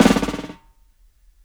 SNARE BUZZ.wav